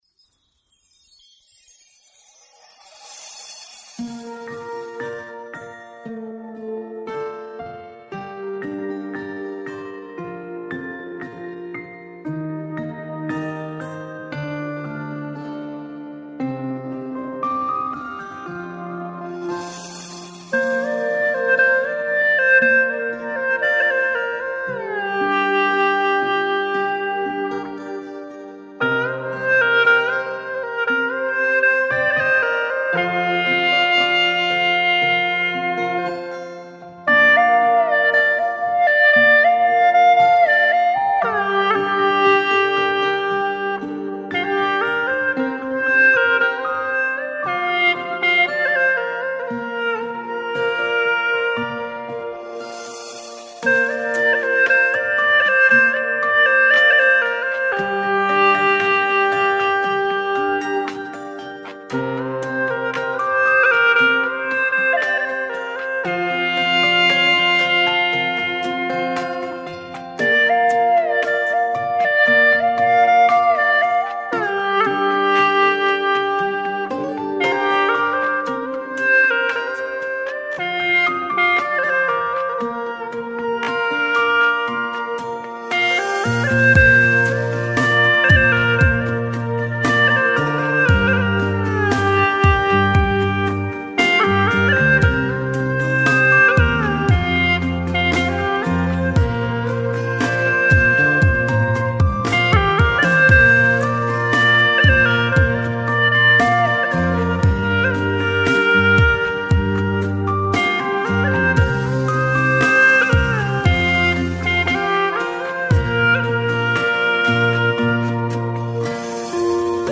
调式 : 降B 曲类 : 流行